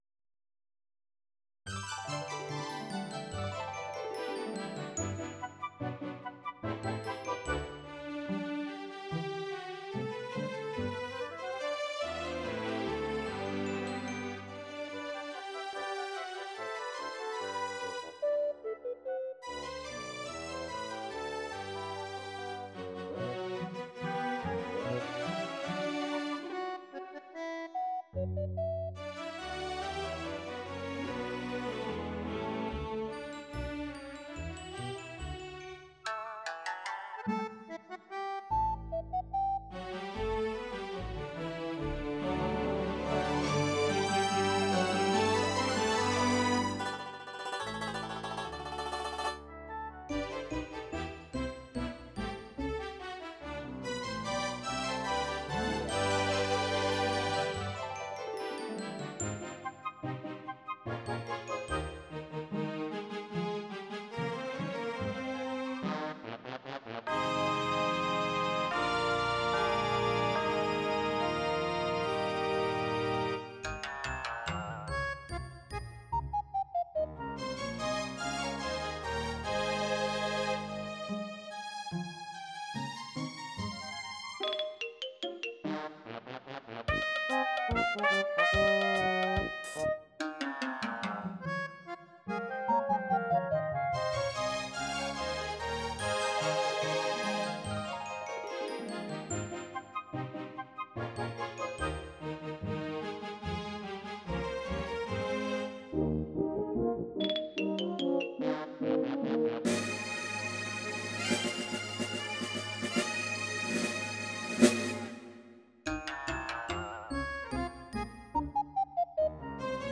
MIDI
Decided to render it with the SC-88 map this year.